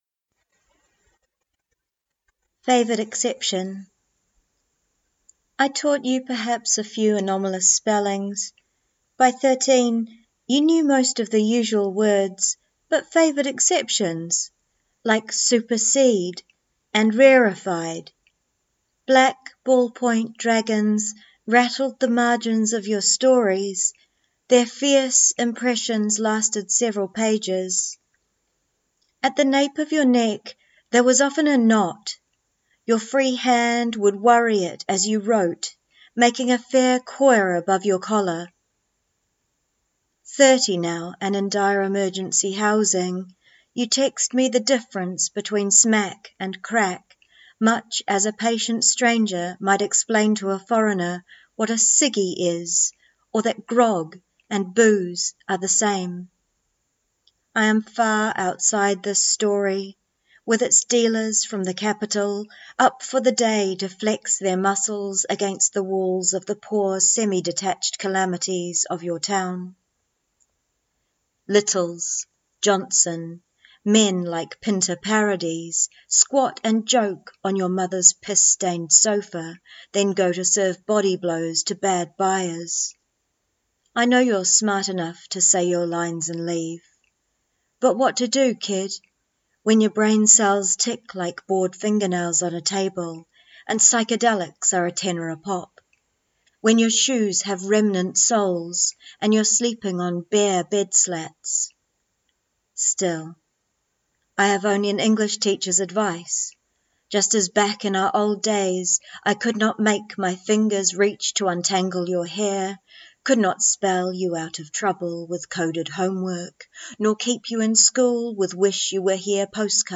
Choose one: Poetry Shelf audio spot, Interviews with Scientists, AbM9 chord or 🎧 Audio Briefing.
Poetry Shelf audio spot